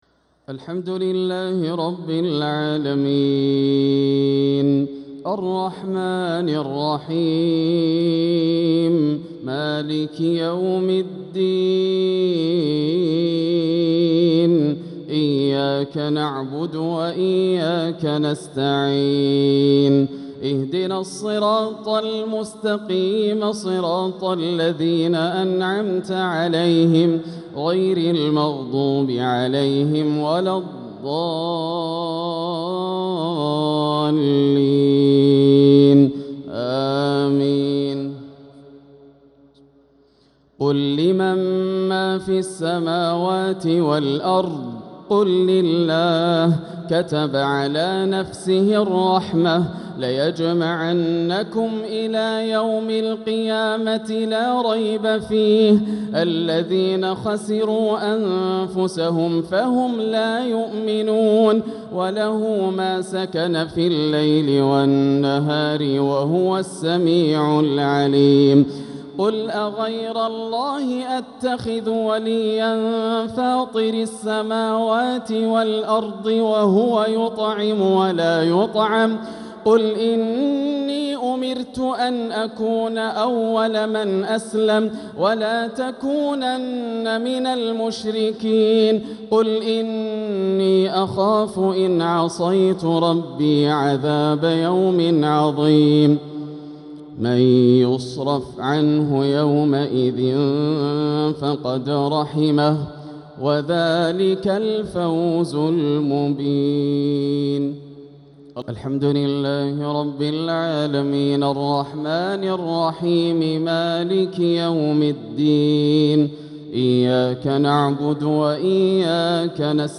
عشاء الأربعاء 5-9-1446هـ من سورة الأنعام 12-18 | Isha prayer from Surat al-An`am 5-3-2025 > 1446 🕋 > الفروض - تلاوات الحرمين